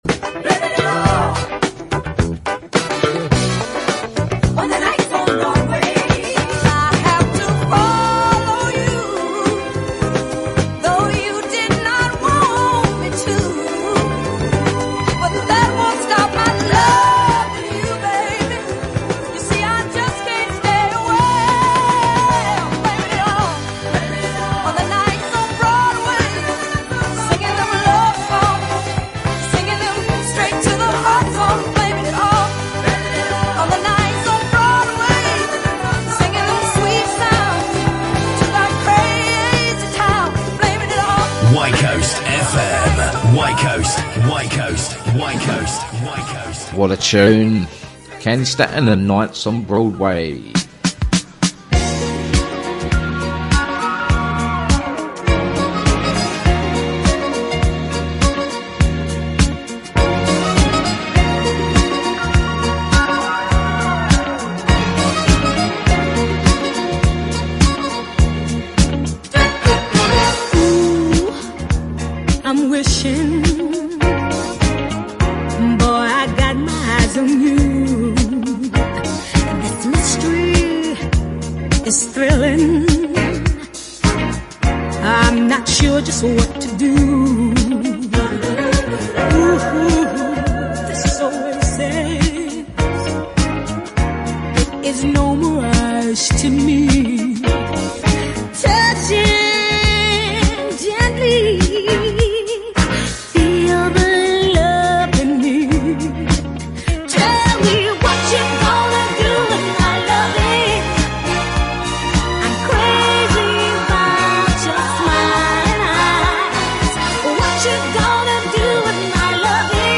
soul, funk, rare groove, and disco